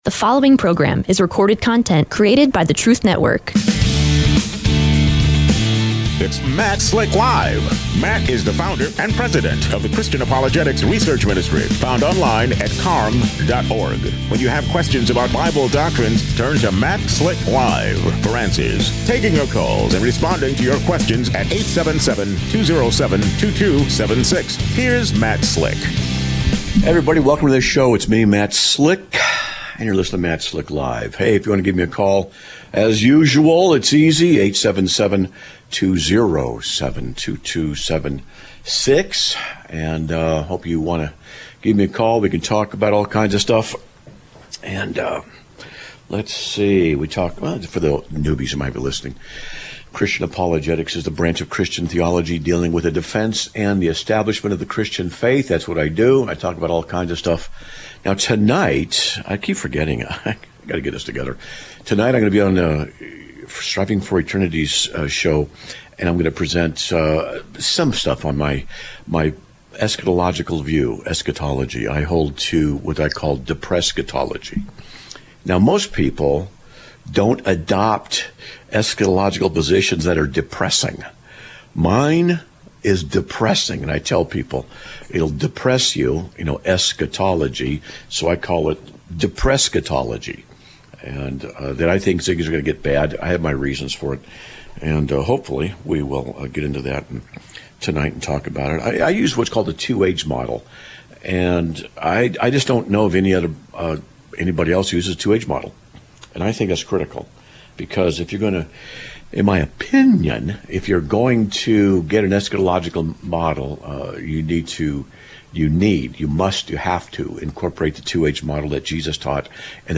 Ministry and Politics--Do They Mix?/A Talk on DEI, Slavery, and Socialism/ A Caller Recommends a Movie about Slavery